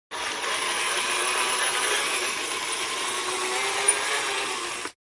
fo_toy_motor_06_hpx
Small toy motor spins at variable speeds. Motor, Toy Buzz, Motor